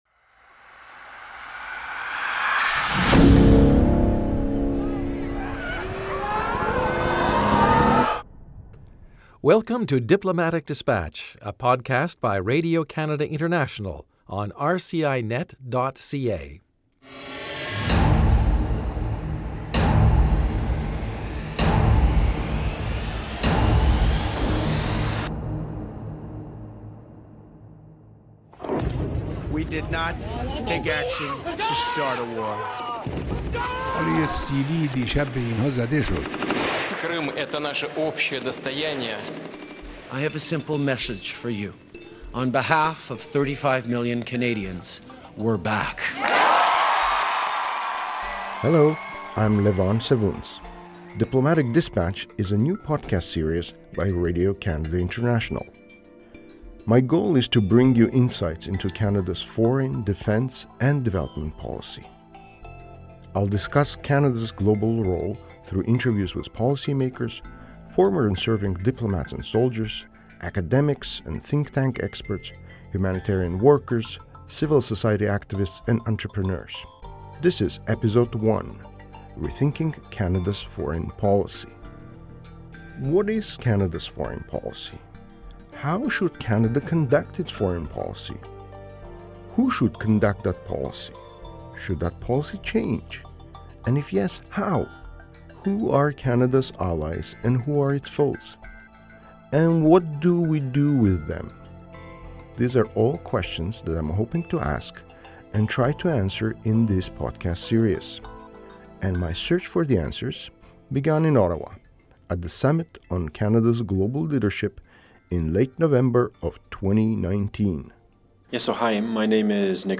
My search for the answers began in Ottawa, at the Summit on Canada’s Global Leadership in late November of 2019.